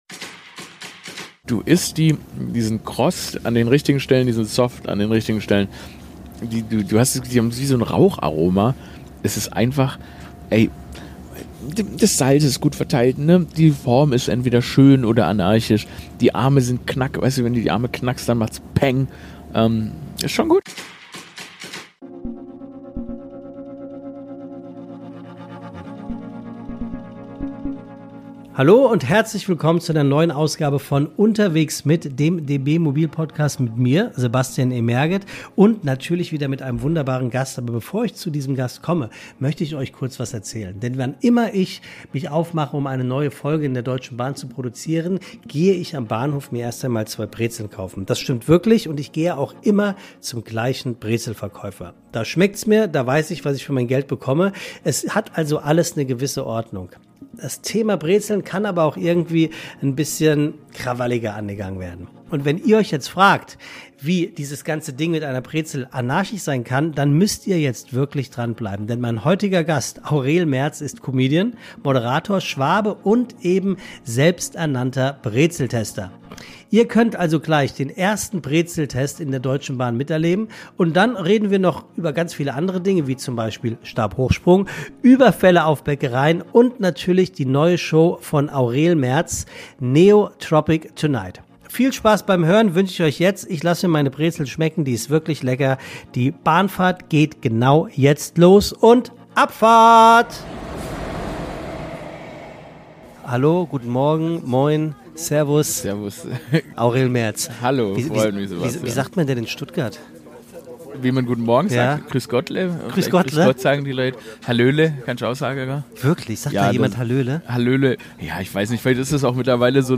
Ein leichtes, amüsantes Gespräch über Narzissmus, Spießigkeit, Mertz‘ Liebe zu Berlin und über den Moment, als ihm von einem Menschenchor „Halt dein Maul“ entgegengerufen wurde.